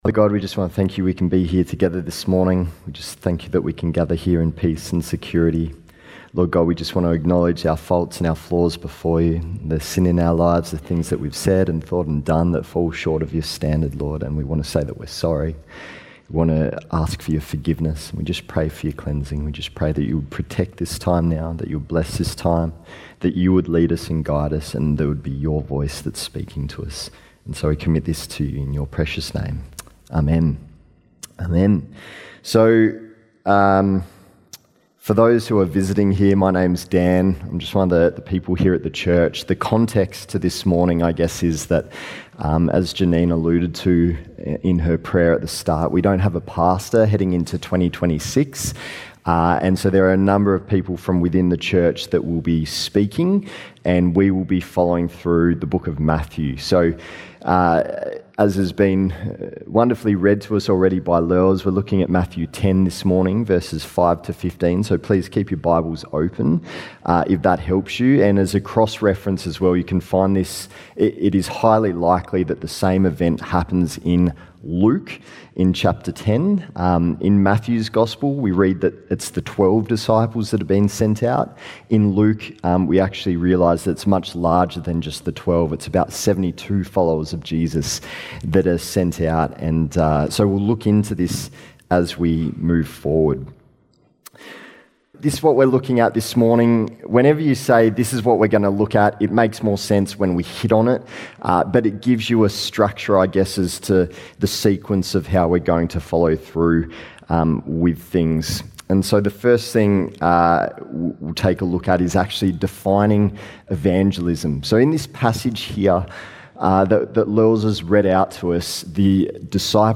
Summerhill Baptist Church Sermons